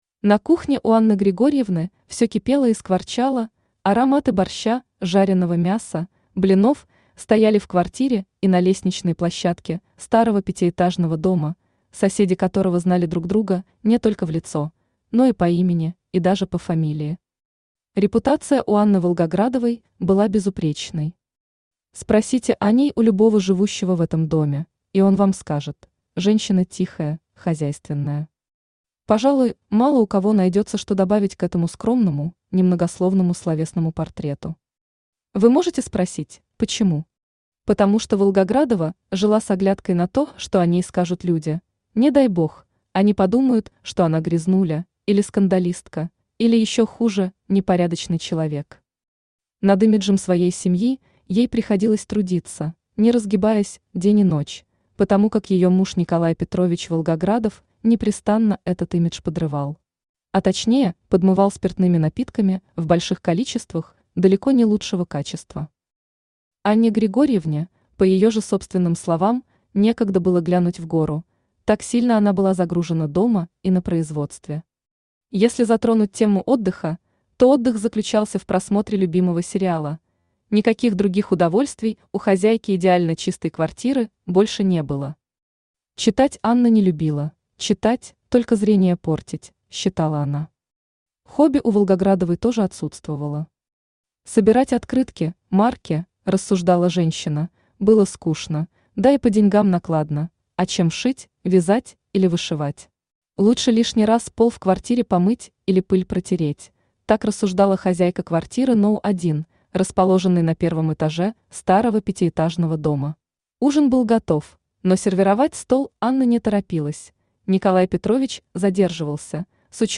Aудиокнига Случай из жизни Автор Виктория Познер Читает аудиокнигу Авточтец ЛитРес.